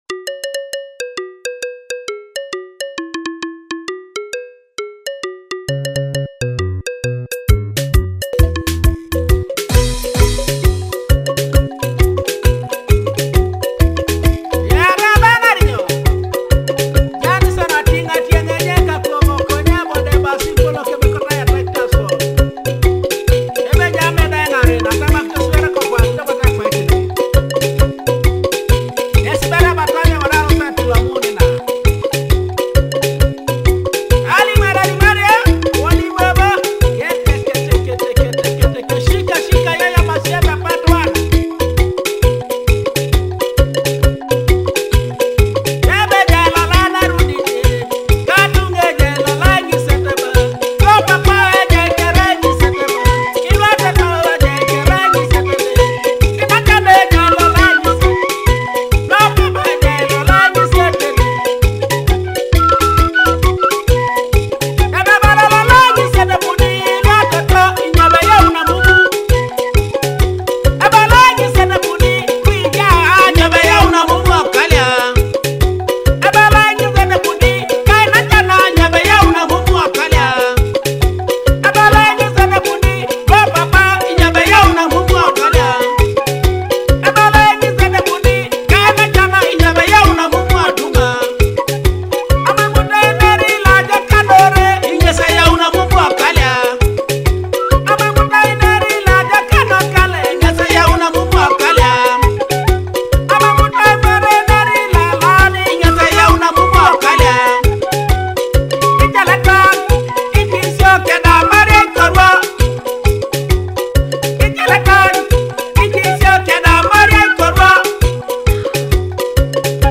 Teso cultural and traditional rhythms in Akogo and Adungu